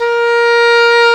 Index of /90_sSampleCDs/Roland LCDP04 Orchestral Winds/WND_English Horn/WND_Eng Horn 2